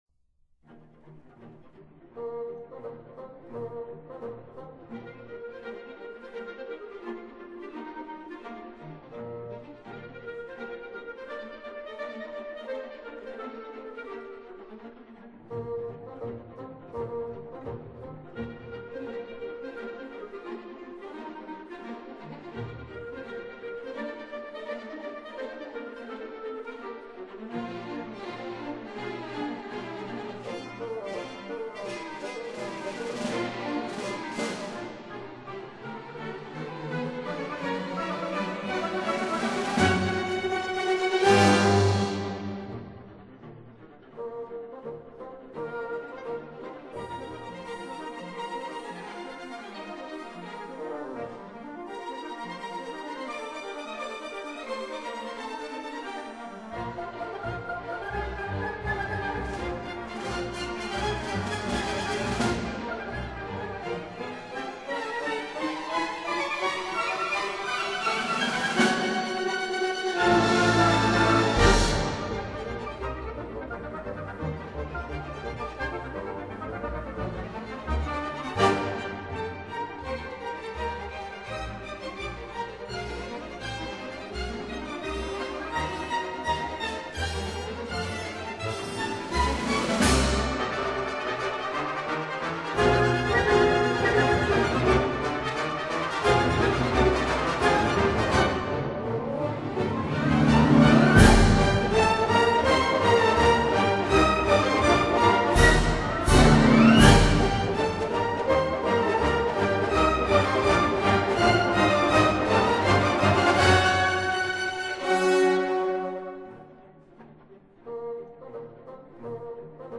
•(02) Symphony No. 1 in C sharp minor, Op. 18
•(04) Symphony No. 2 in C minor, Op. 19